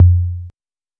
Tuned tom samples Free sound effects and audio clips
• Old School Long Tom Drum F Key 35.wav
Royality free tom drum tuned to the F note. Loudest frequency: 96Hz
old-school-long-tom-drum-f-key-35-TKn.wav